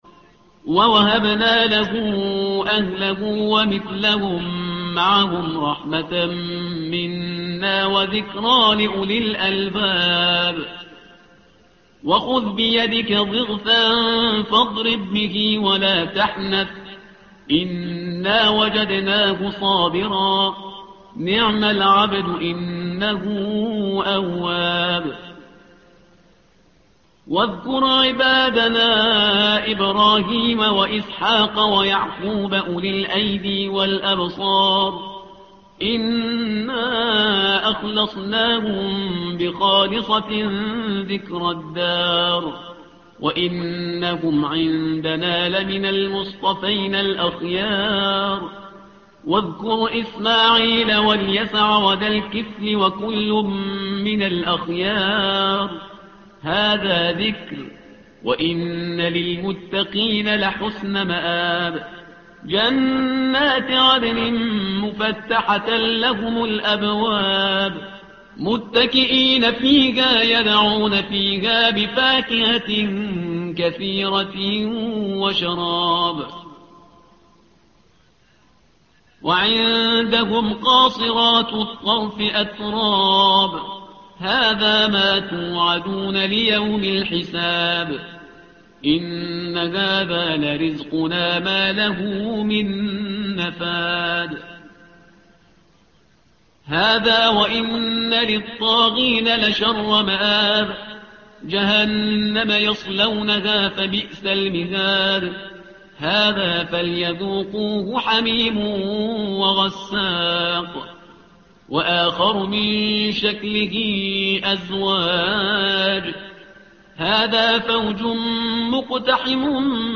الصفحة رقم 456 / القارئ